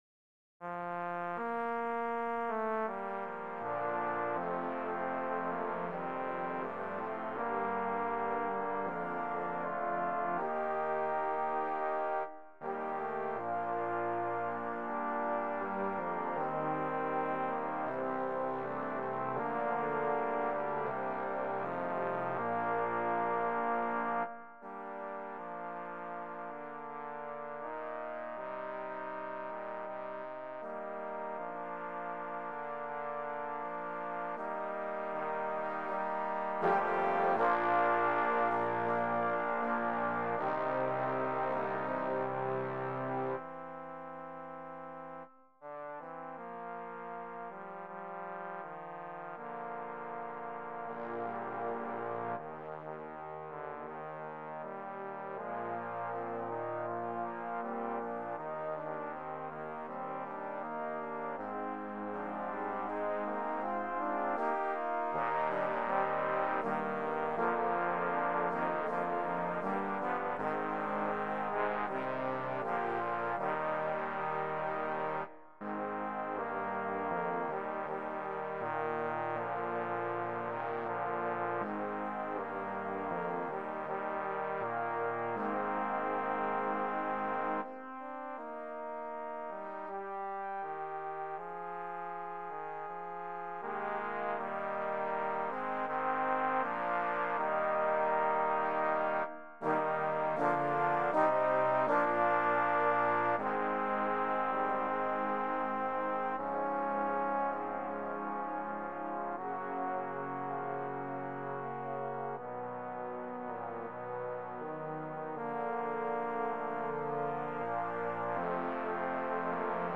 Voicing: Trombone Ensemble